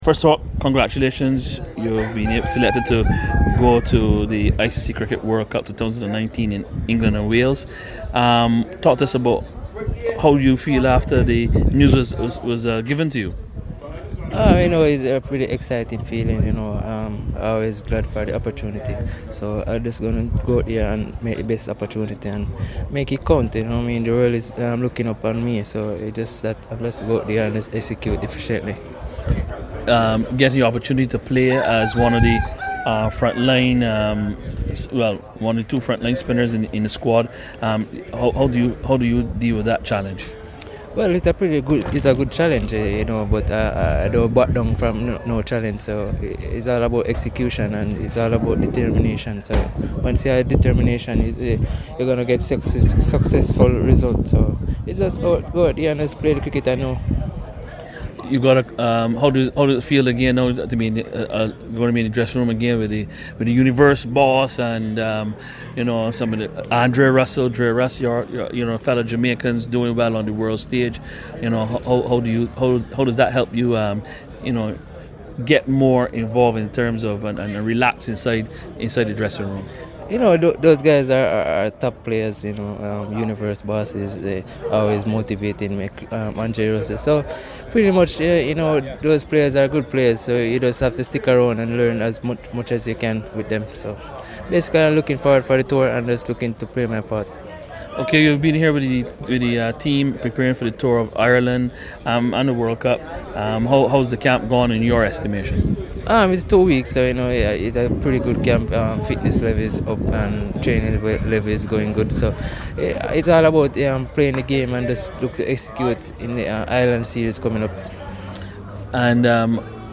Fabian Allen spoke to CWI Media as West Indies wrapped up their training camp taking place at the Cave Hill campus of the University of the West Indies here.